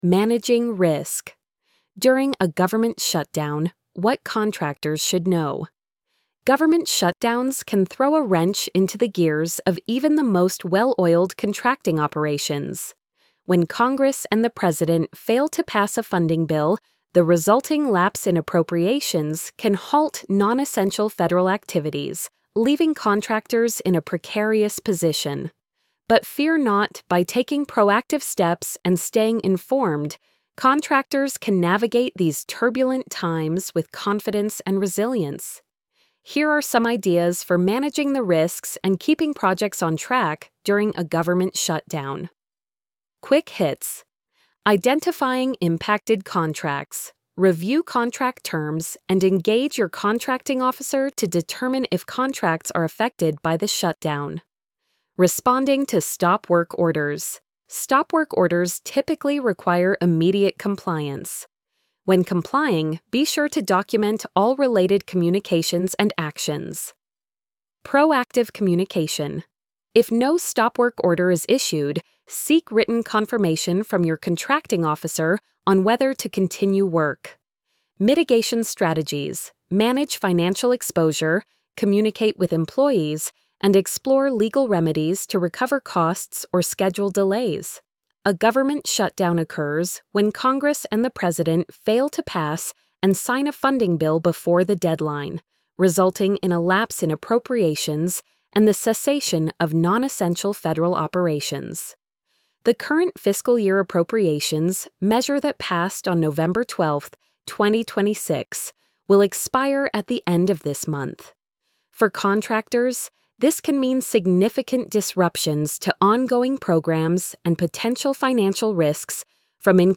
managing-risk-during-a-government-shutdown-what-contractors-should-know-tts-1.mp3